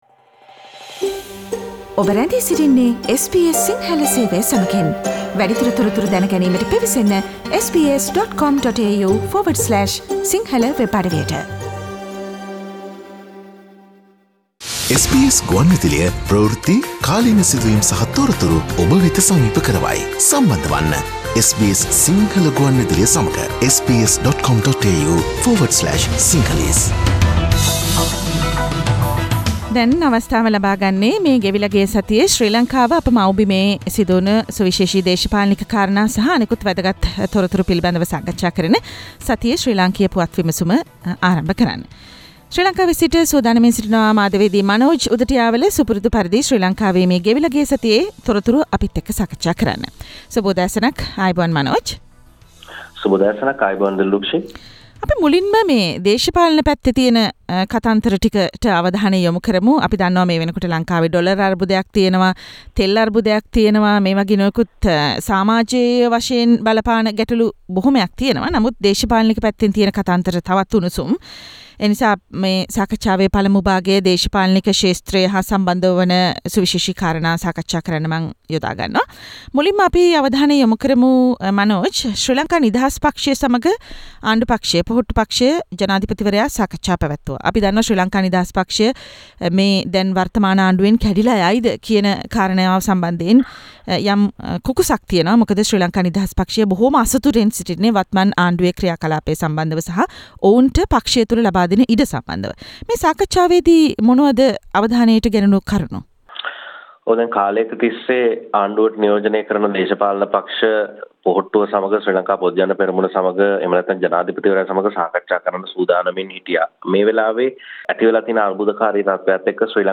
පොහොට්ටුව හදන්නට යන ජාතික ආණඩුවේ අගමැති රනිල් වේවිද යන්න ගැන ලොකු සැකයක්: සතියේ ශ්‍රී ලාංකීය පුවත් විමසුම